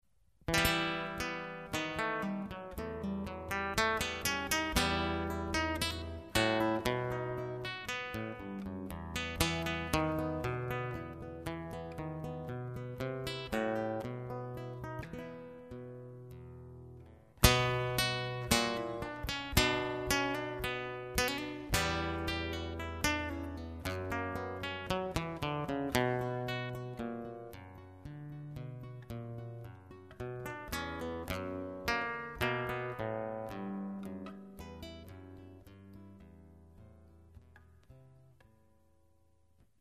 Gitarrist
Partita-Entree (Barock)